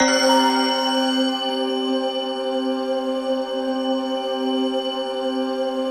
ATMOPAD03 -LR.wav